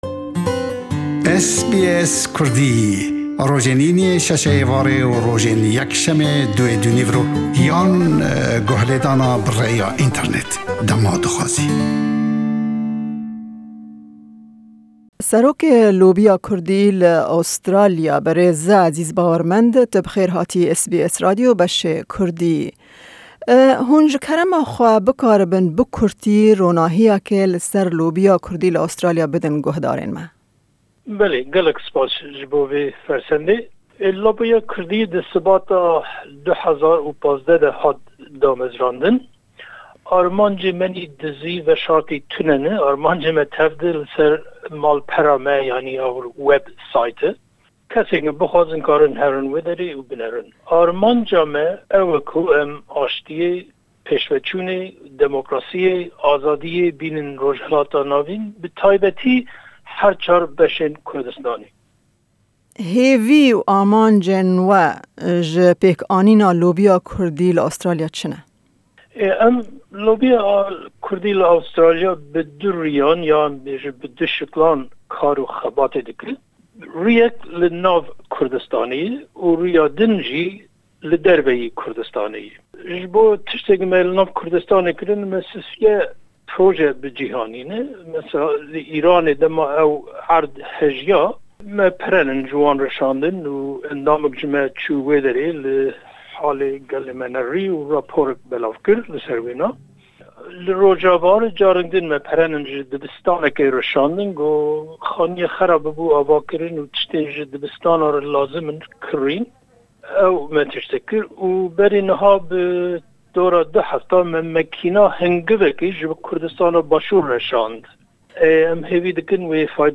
Lobiya Kurdî li Australya di sala 2015an de hat demezrandin. Me derbarê demezrandin, hêvî û amancên Lobiyê hevpeyvînek